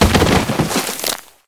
liondead.ogg